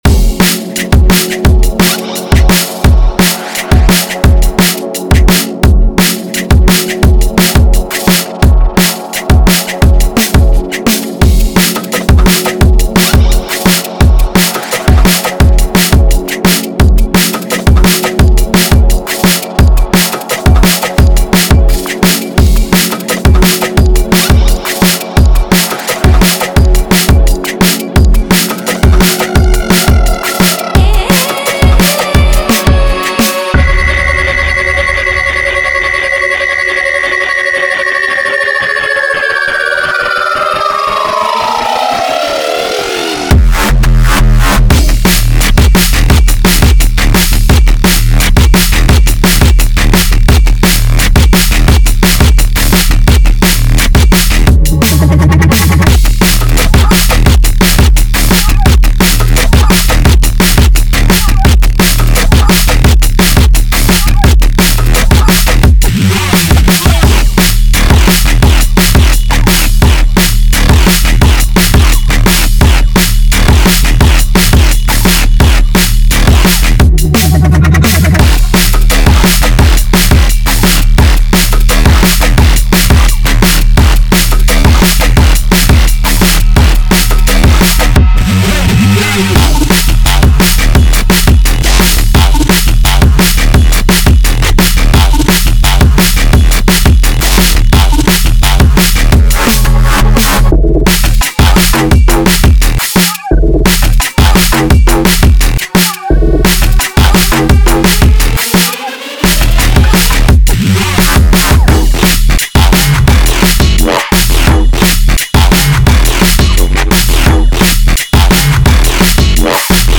Russian neurofunk duo